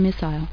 c_missile.mp3